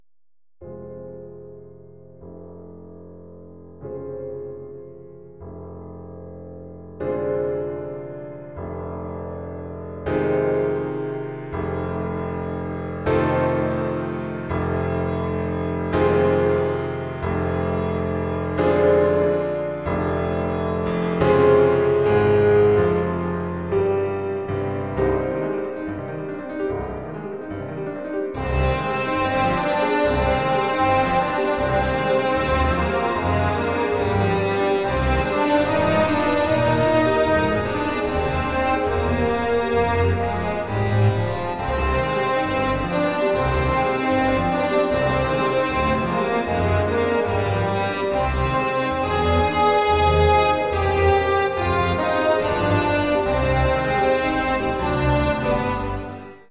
First 55 seconds of my rendering of Rachmaninov Piano Concerto No. 2